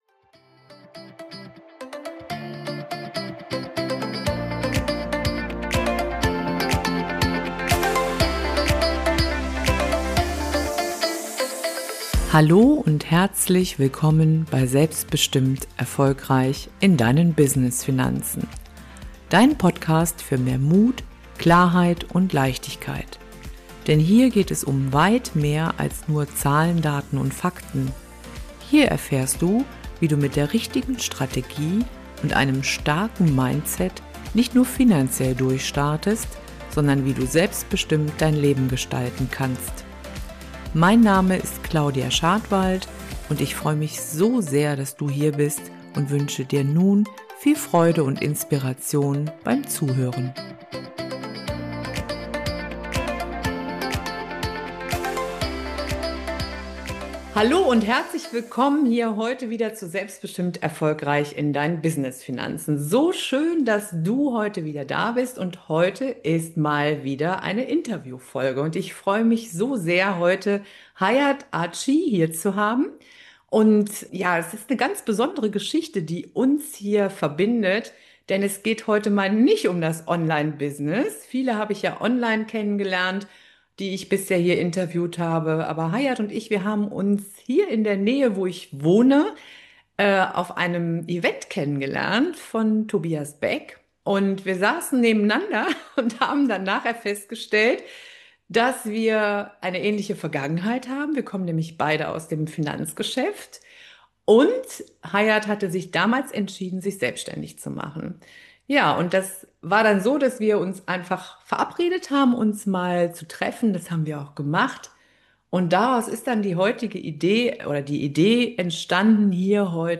Wie du dich selbst führen lernst – und Vertrauen wichtiger wird als Leistung. Interview